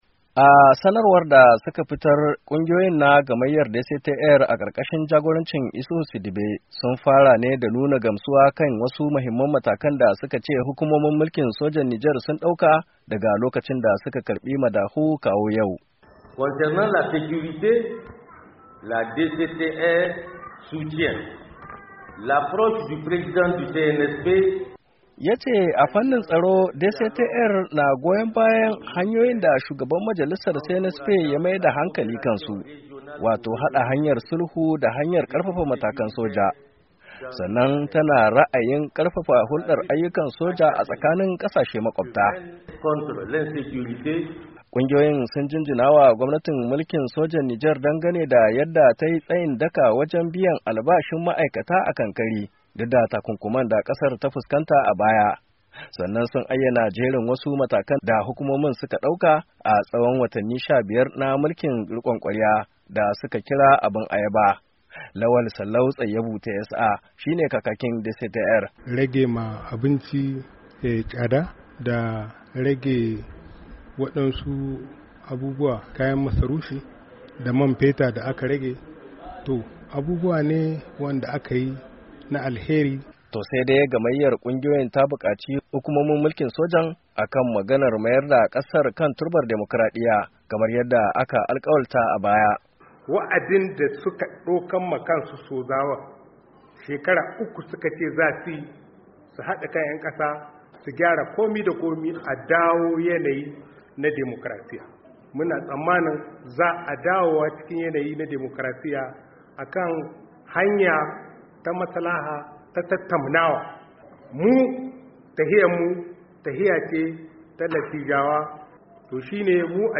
Kungiyoyin sun yi wannan kira ne a taron manema labarai da suka kira a birnin Yamai.